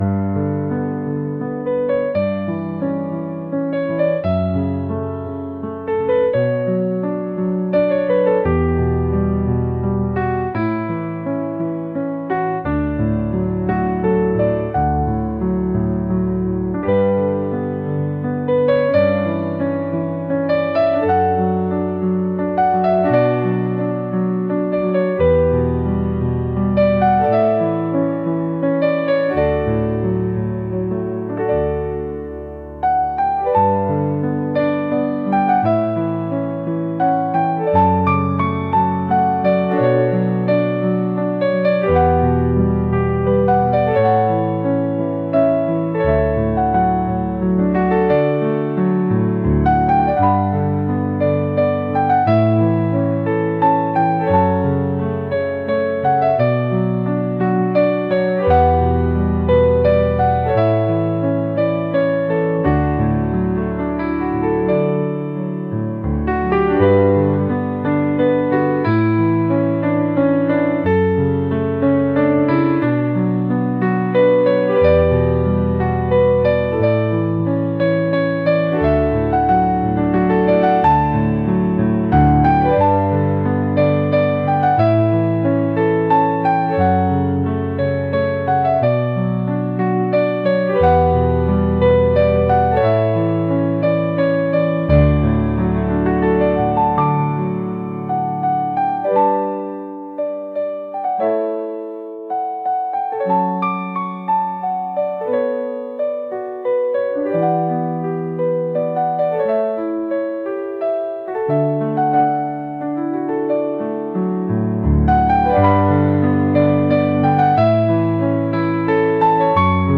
母に感謝を送る愛情を込めたピアノ曲です。